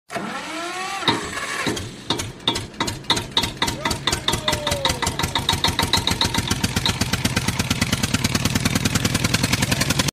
Eicher Traktor Start Klingelton
Eicher Traktor Klingelton Hermann Lanz Original Traktor Klingelton
Kategorie: Klingeltöne
eicher-traktor-start-klingelton-de-www_tiengdong_com.mp3